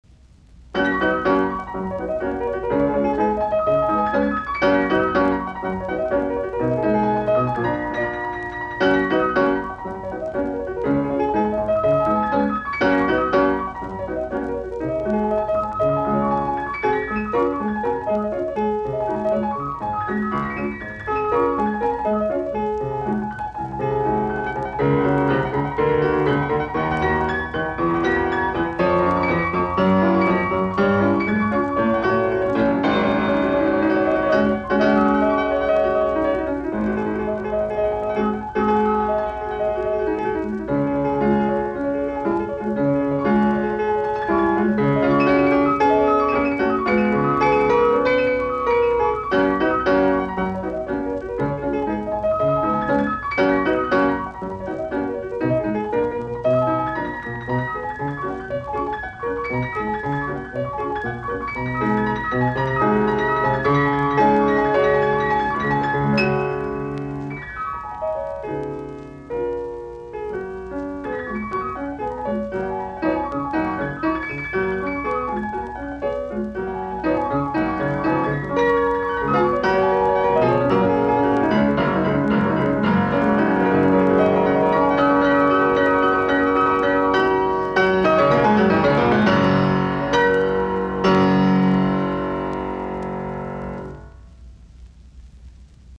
Etydit, piano, op10
Soitinnus: Piano.